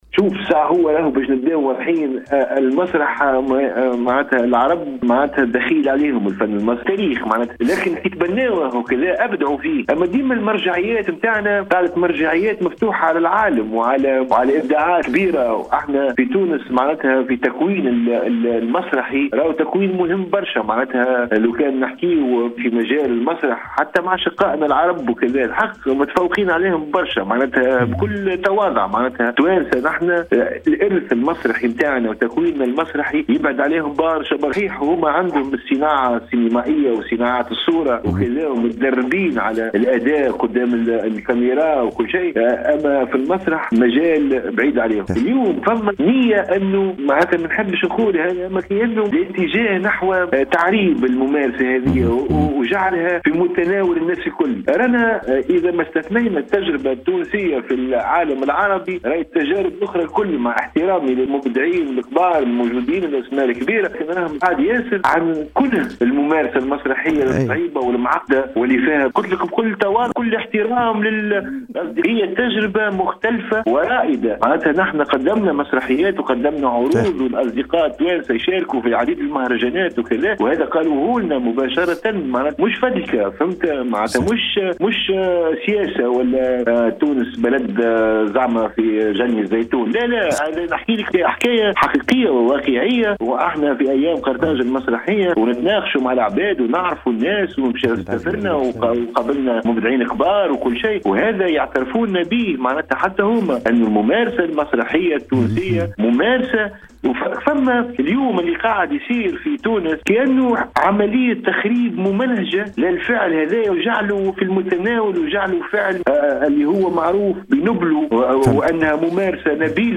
تسجيل الممثل التونسي عاطف بن حسين :
أكد الممثل التونسي عاطف بن حسين خلال مداخلته في برنامج ” SILENCE-ACTION ” على أوليس أف أم أن الساحة المسرحية في تونس تتعرض لعملية تخريب ممنهجة من طرف بعض الهياكل المتداخلة في القطاع .